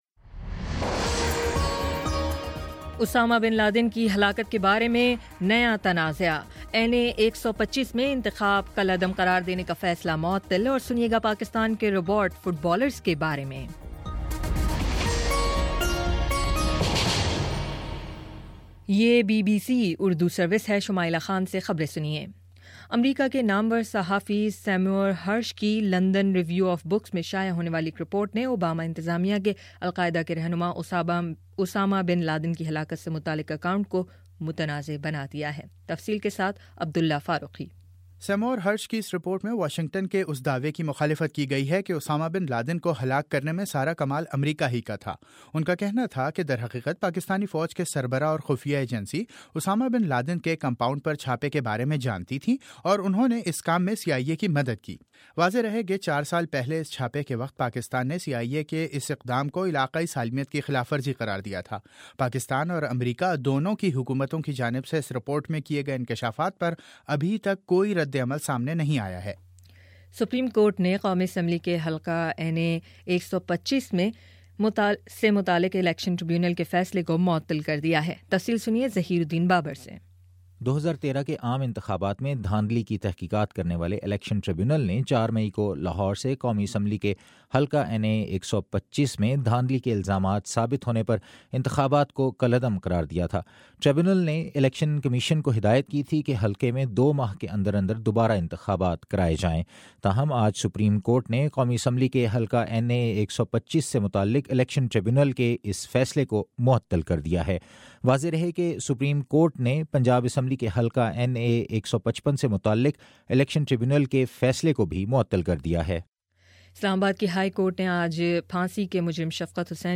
مئی 11: شام چھ بجے کا نیوز بُلیٹن